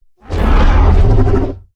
Growl1.wav